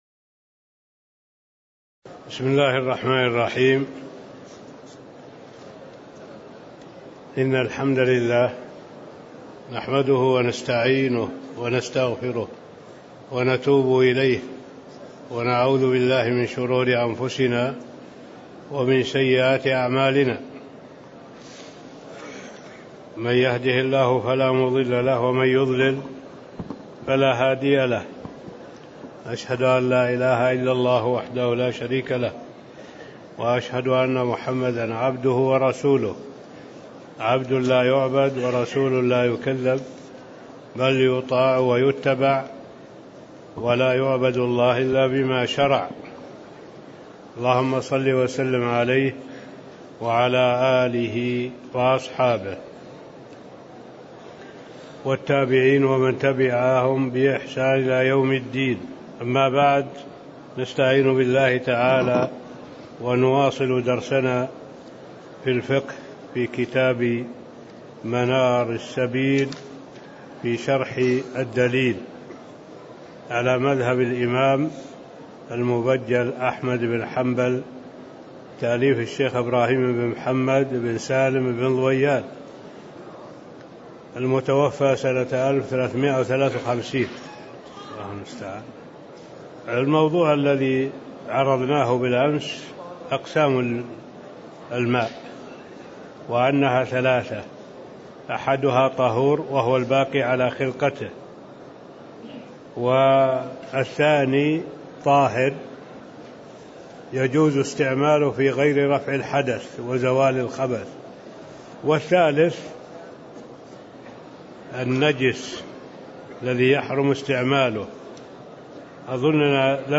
تاريخ النشر ١٧ جمادى الأولى ١٤٣٦ هـ المكان: المسجد النبوي الشيخ